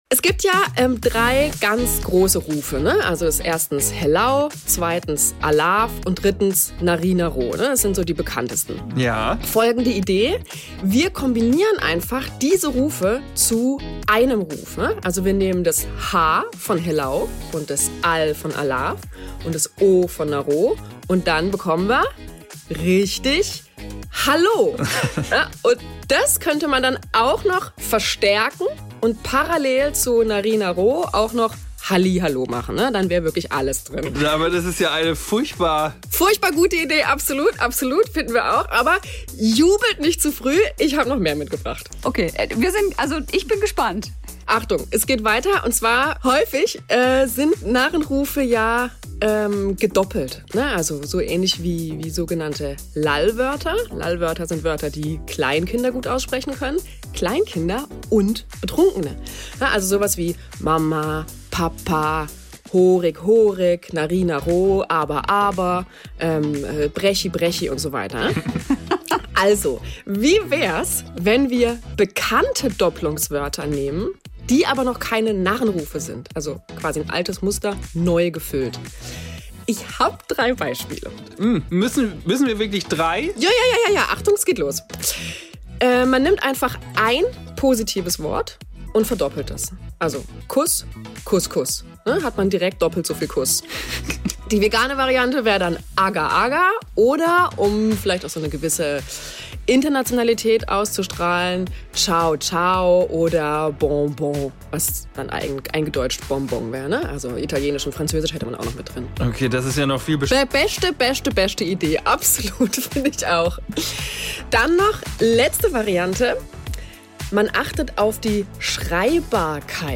Dafür haben wir uns unsere Comedy-Kollegen von Luksan Wunder (kennt ihr bei SWR3 zum Beispiel von Monikers Sprüchle-Ecke) geschnappt, hört hier rein: